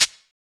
High-Hat Sample B Key 05.wav
Royality free hat sample tuned to the B note. Loudest frequency: 4974Hz
high-hat-sample-b-key-05-BUk.mp3